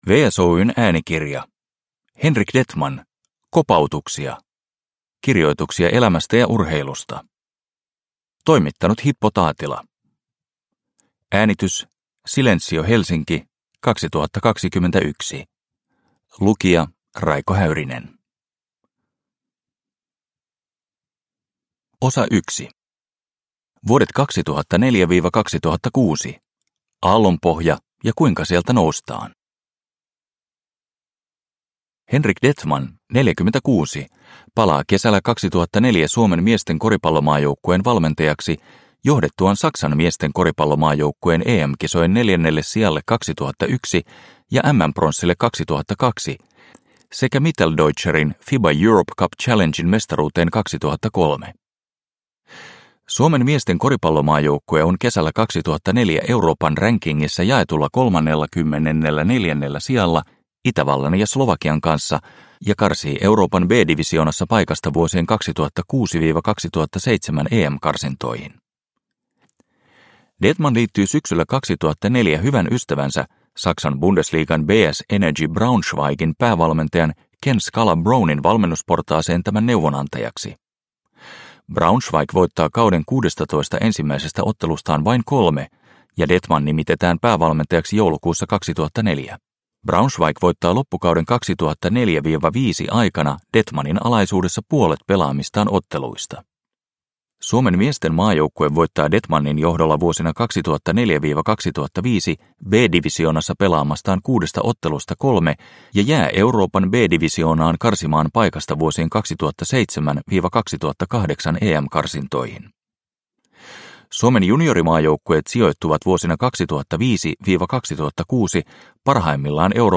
Kopautuksia – Ljudbok – Laddas ner